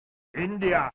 india-street-fighter-2-turbo-sound-effect-free.mp3